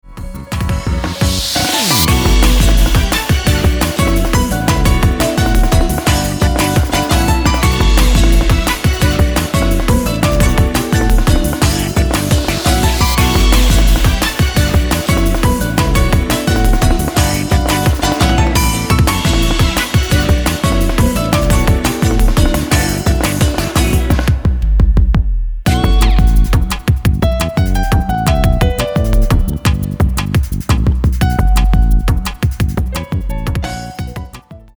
■All music composition , wards , arrengement & guitar play